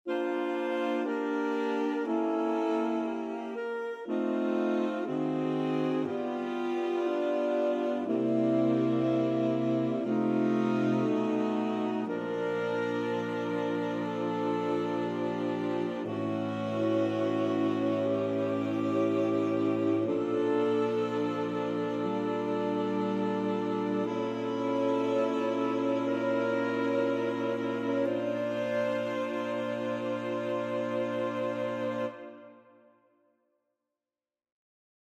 Key written in: B♭ Major
How many parts: 4
Type: Barbershop
All Parts mix: